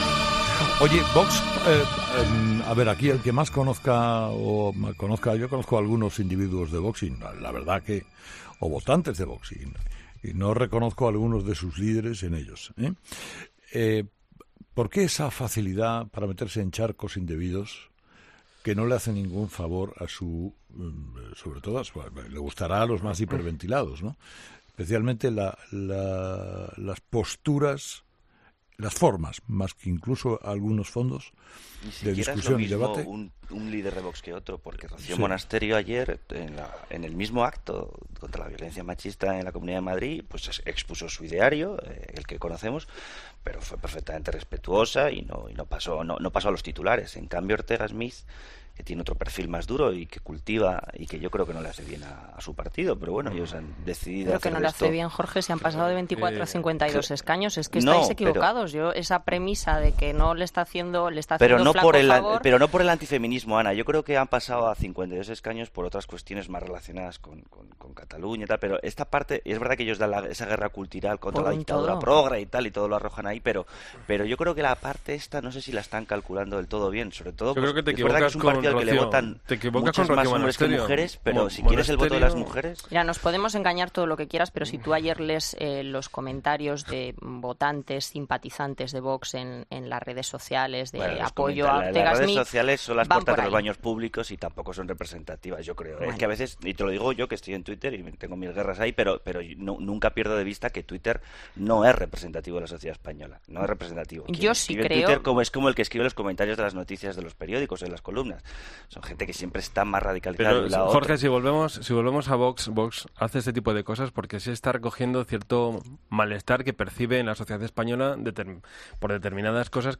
Los tertulianos de 'Herrera en COPE' han opinado sobre el incidente de ayer entre el representante de Vox y una víctima de violencia de género